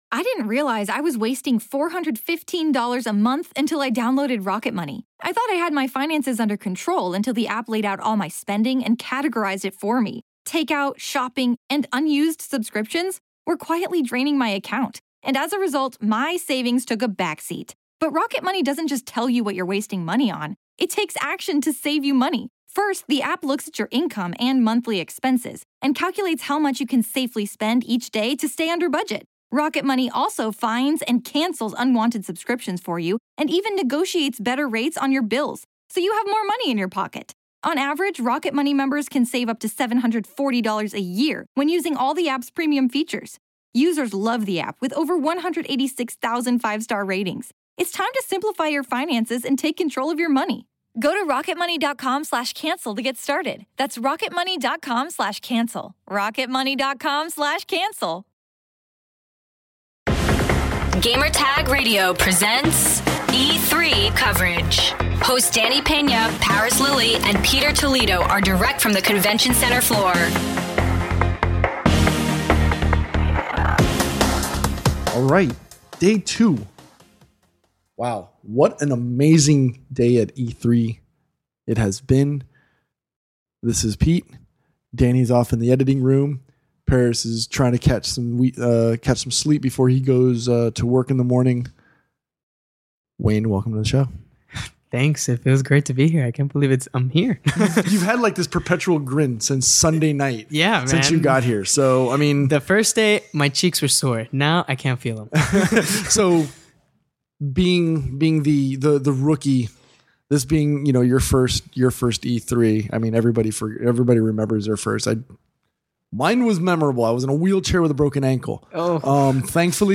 E3 2018: Day 2 Roundtable Discussion